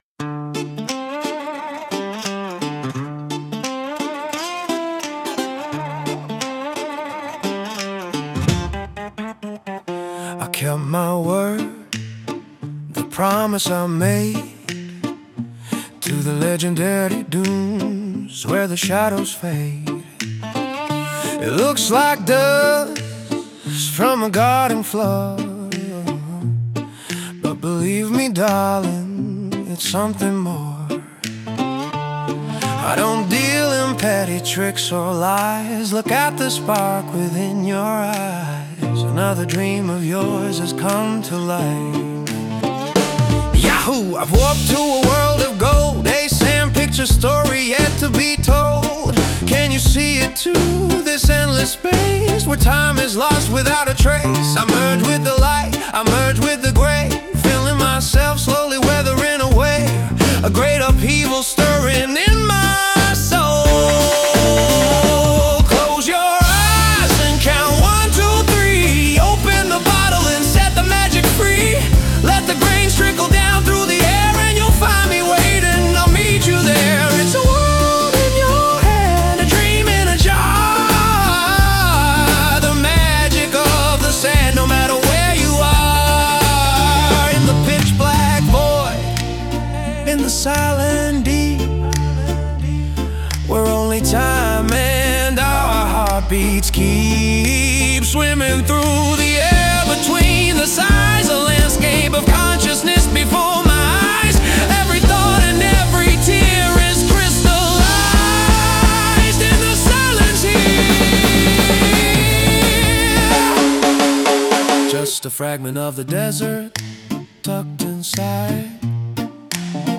20年くらい前に書いた「コルク瓶の魔法」という詩をリライトし、AI使って曲にしてみました。